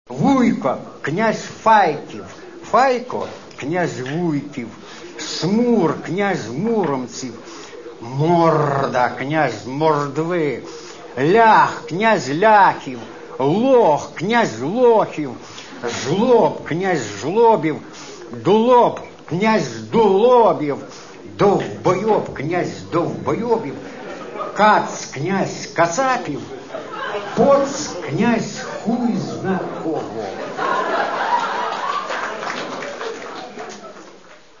Ця платівка, запис літературно-музичного вечору у київському ресторані "Хорив", являє собою цікавий трьохлінійний конгломерат – дивіться самі.